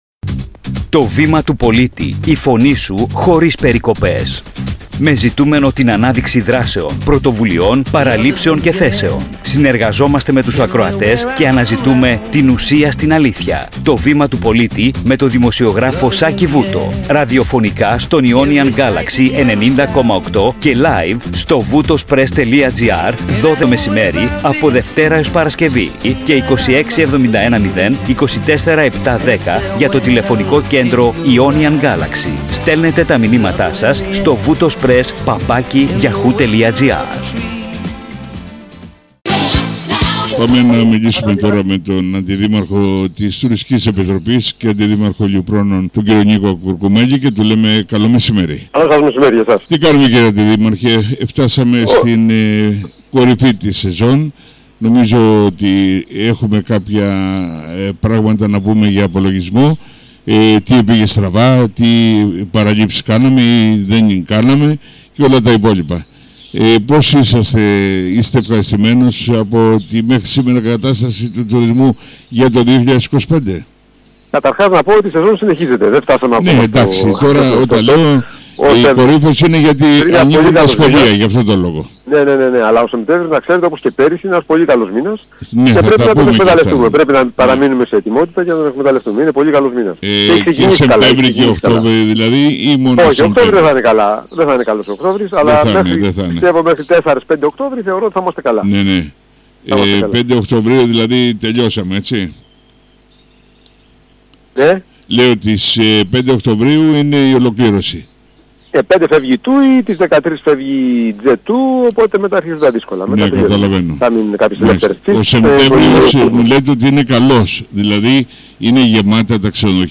✦ Συνέντευξη με τον Αντιδήμαρχο Τουρισμού Κεφαλονιάς, κ. Νίκο Κουρκουμέλη – Voutospress Kefalonia